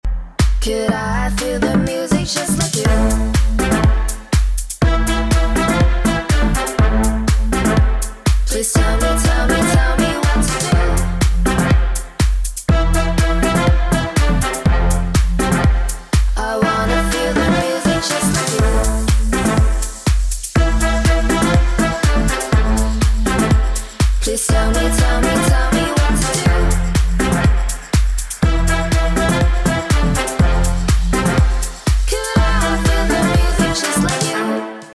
• Качество: 192, Stereo
ритмичные
заводные
женский голос
dance
EDM
future house